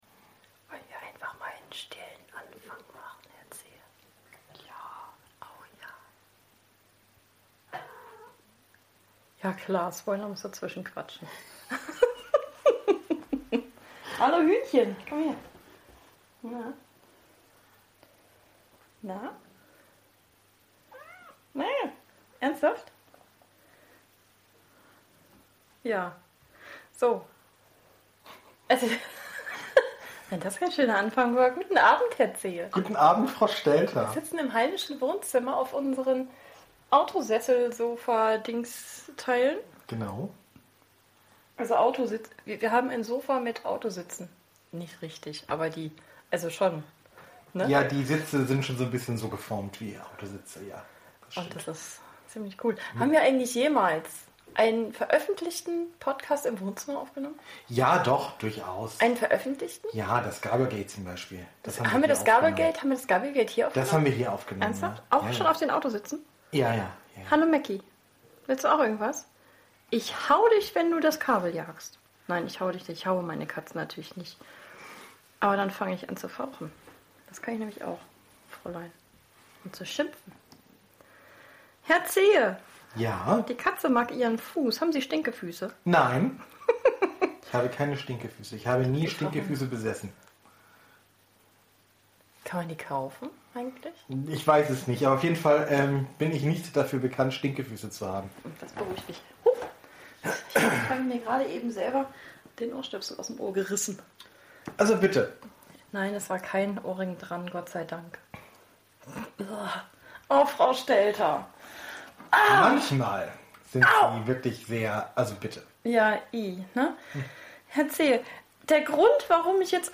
Sabbelei halt, mit eingespielten Lachern, wie immer.(Wir reden über Amazoniens Rezensenten und Rezessionen, und haben noch was anderes parat.